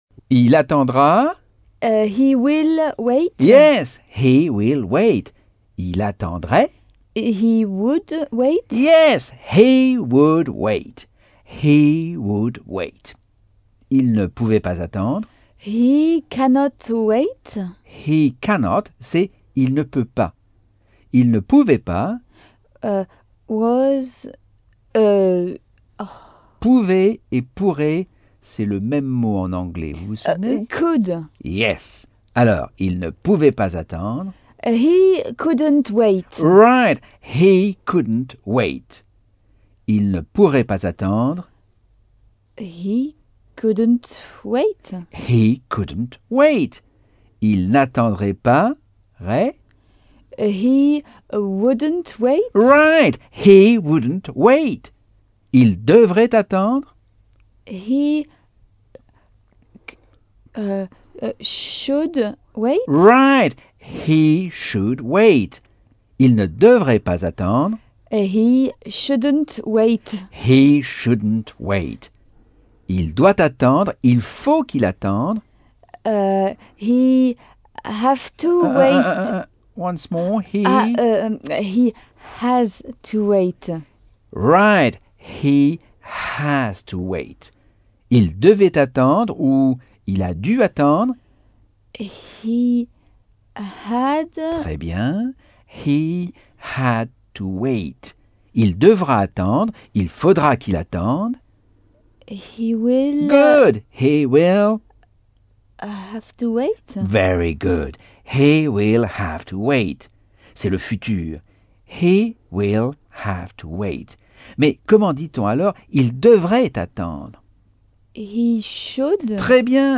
Leçon 9 - Cours audio Anglais par Michel Thomas - Chapitre 7